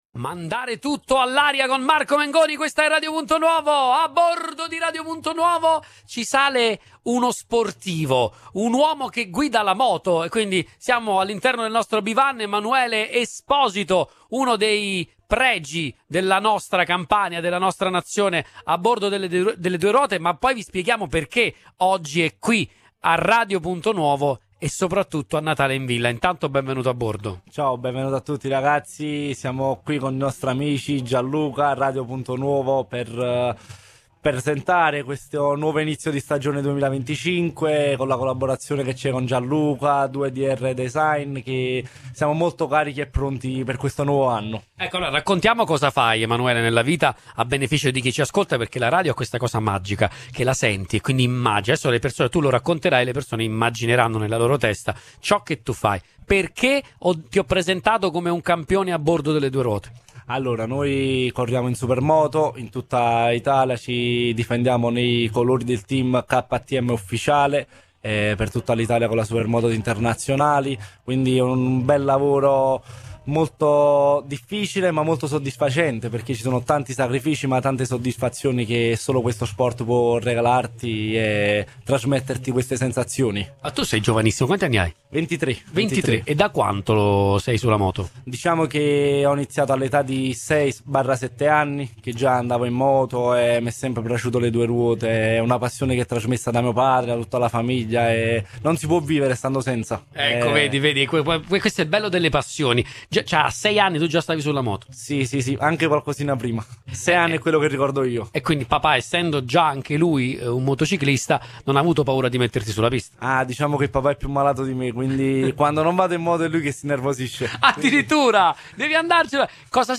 A rendere ancora più coinvolgente l’esperienza, oggi abbiamo trasmesso in diretta dall’evento, raccontando emozioni, sorrisi e i tanti desideri natalizi dei più piccoli.